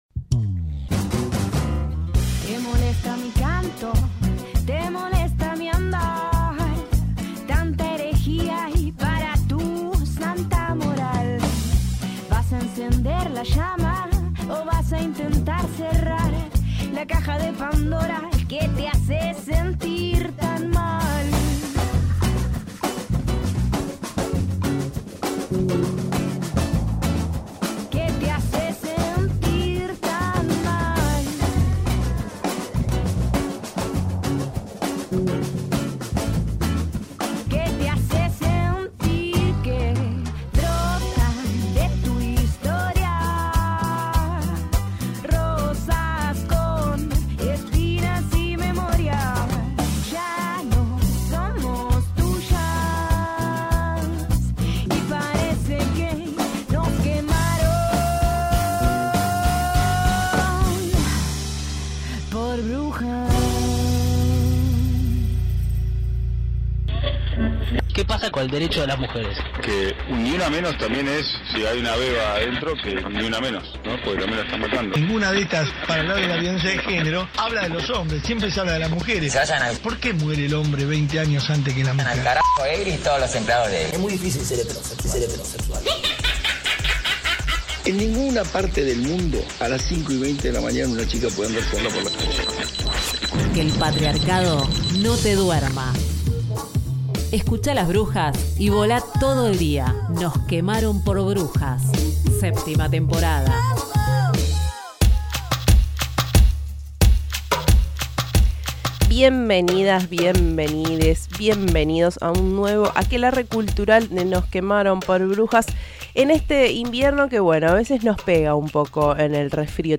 Dale play y sumergite en una charla sobre editorial, poesía y filosofía feminista.